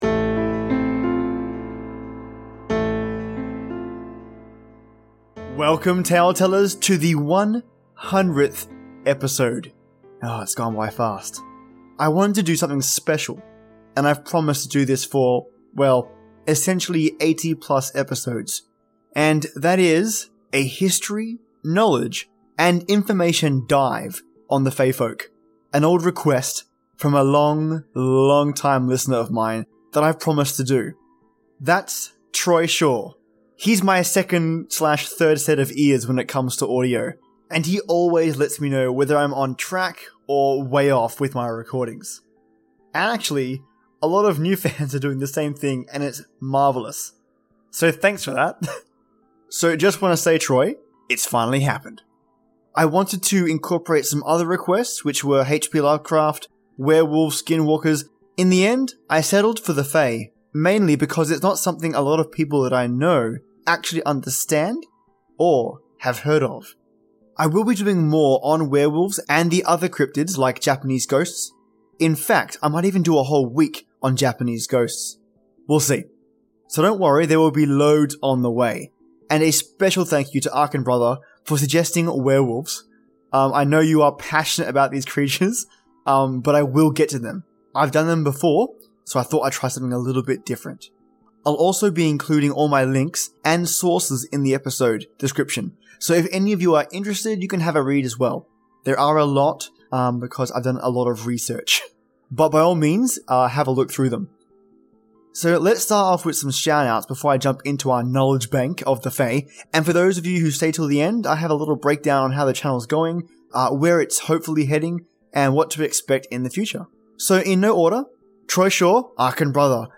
It's 12:30 in Australia and I'm determined to upload todays episode - despite the time and the horrendously loud rain; I'm getting this to you! I discuss the details of Fae Folk, provide the history, types of Fae (general to some specific), story examples, cultural prevalence of Fae and Fairy folk, and sooo much more.